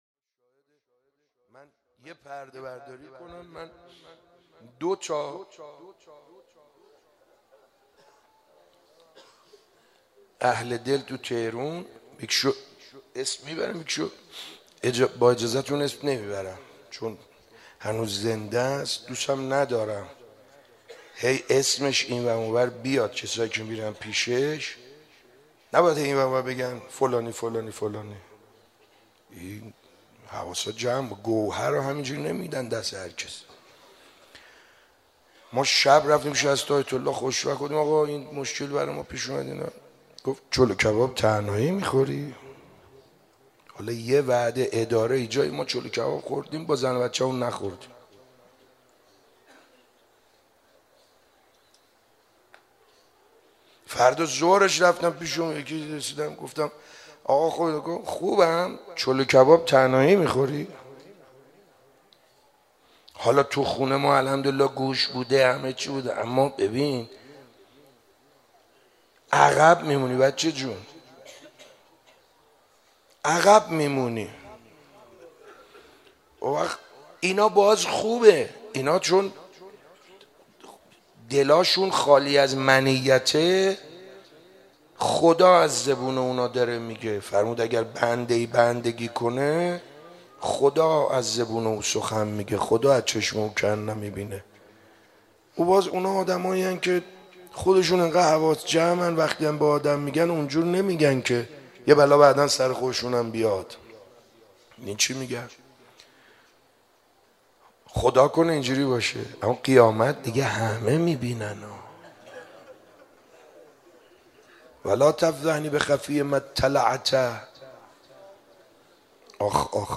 شب18 ماه مبارک رمضان 95_روضه خوانی